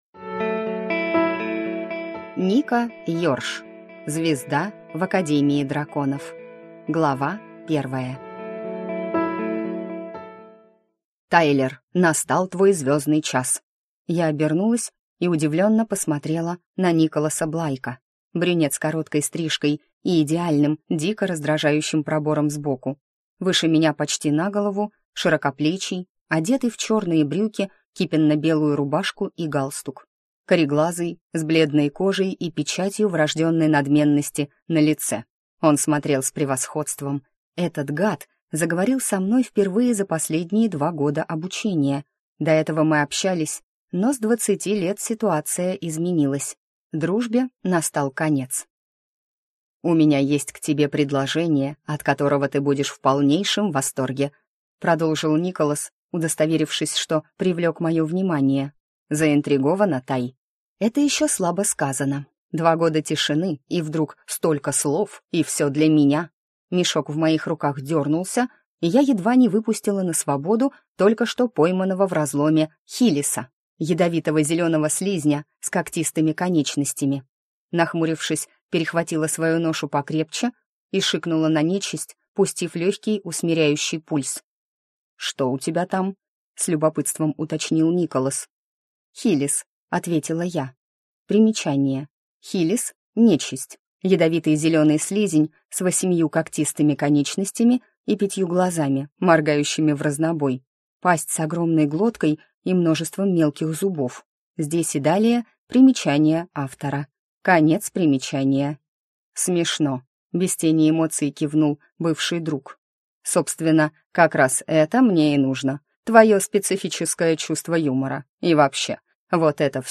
Слушать аудиокнигу Любить нельзя гнобить. Как справляться с неудобными эмоциями полностью